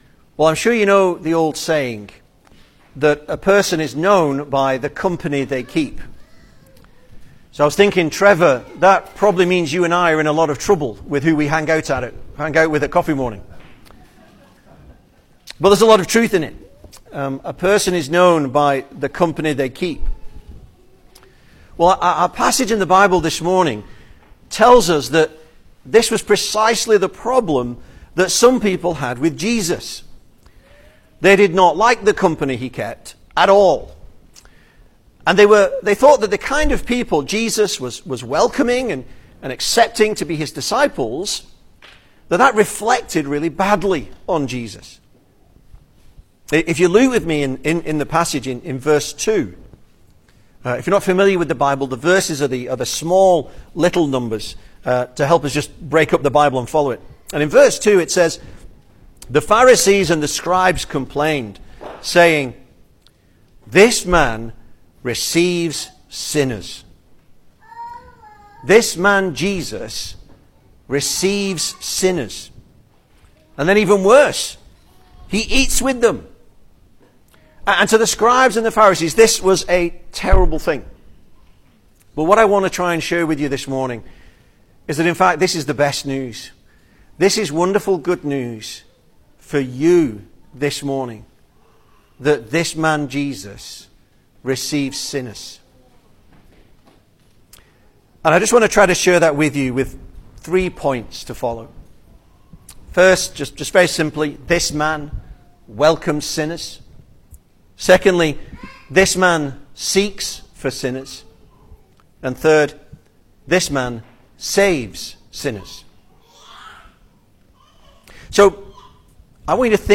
Service Type: Sunday Morning
Single Sermons